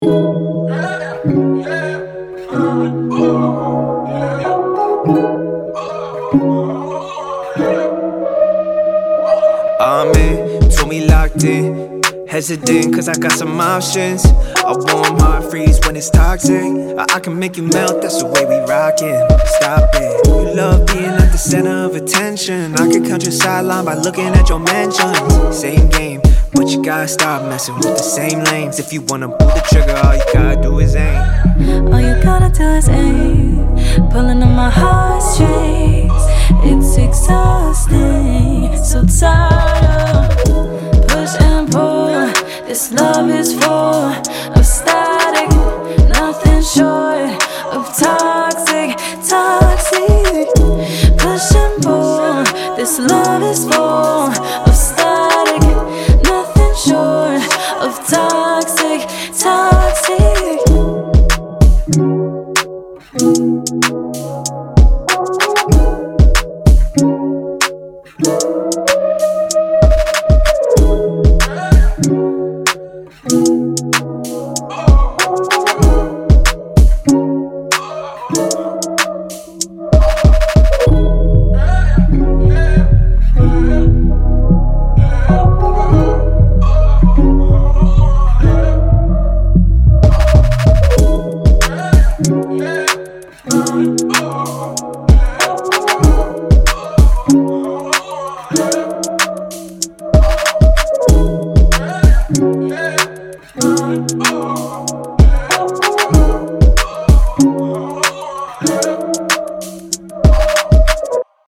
Alternative R&B, R&B
B Maj